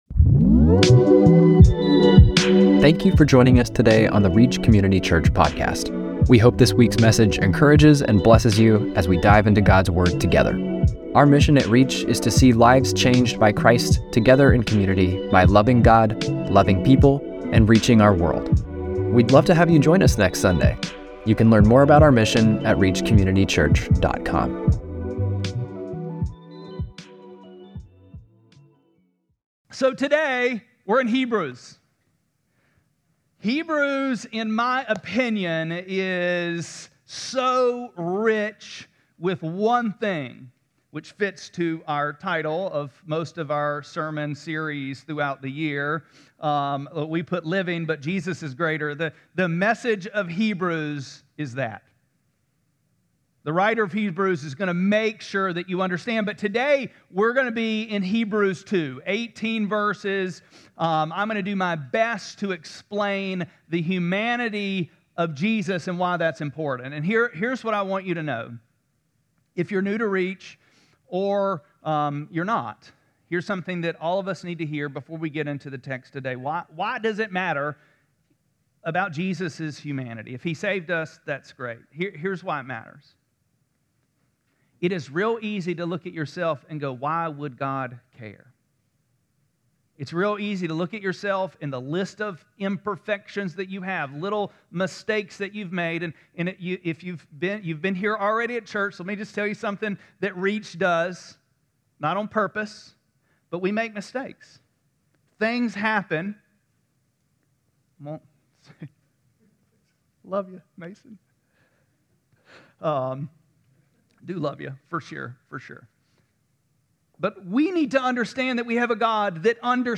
11-2-25-Sermon.mp3